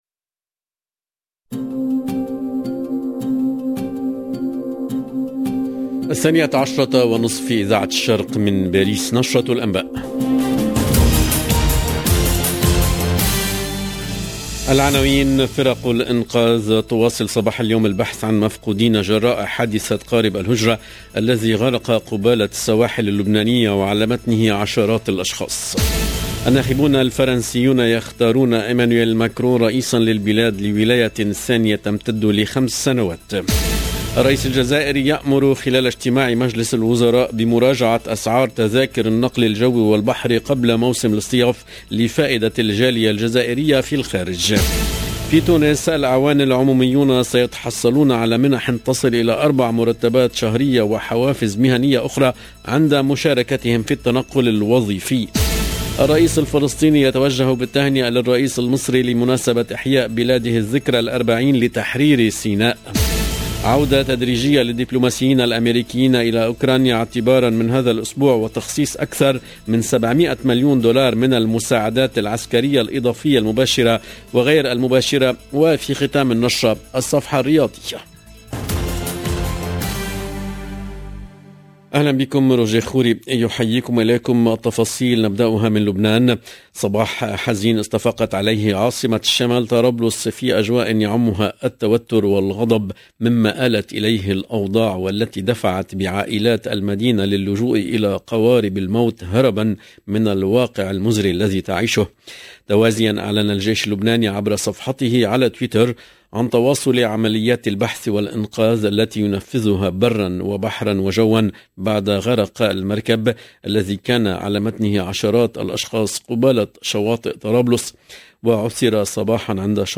LE JOURNAL DE MIDI 30 EN LANGUE ARABE DU 25/04/22